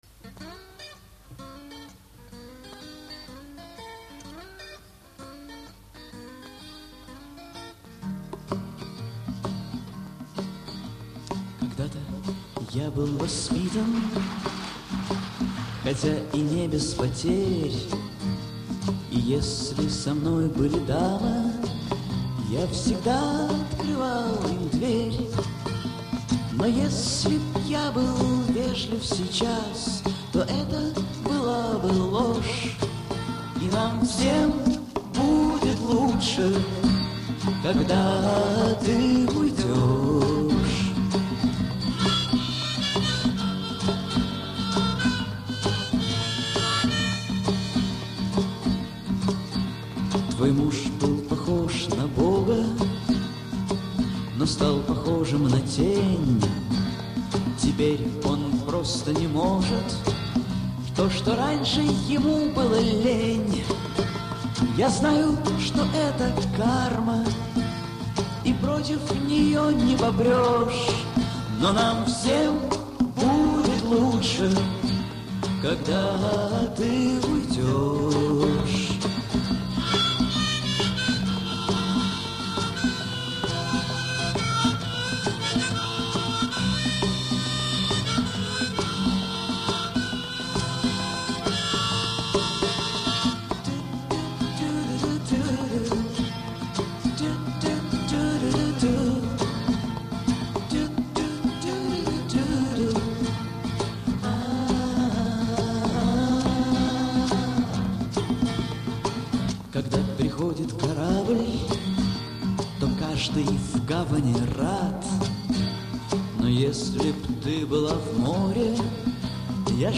Акустический концерт